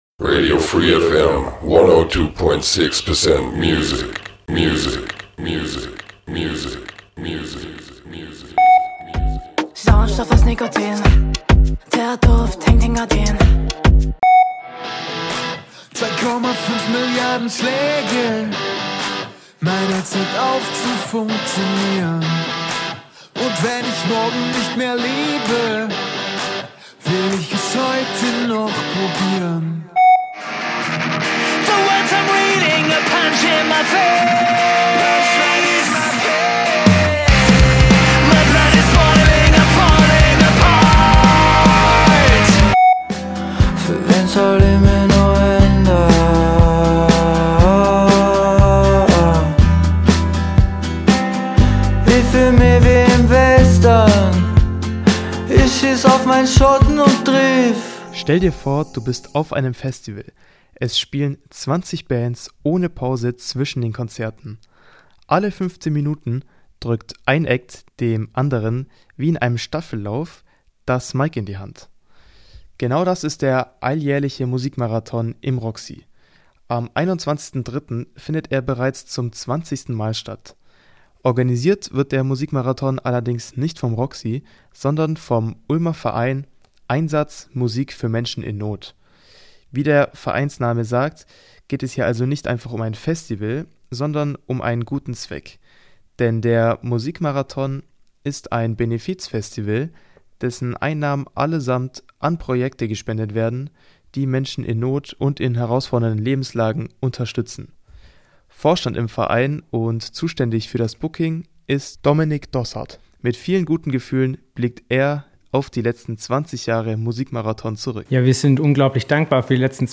Night On Air Interview mit DaDaGegen
Während der Night on Air 2025 haben wir vier Bands zu Gast gehabt, die nicht nur für uns gespielt haben, sondern auch Live Interviews gegeben haben!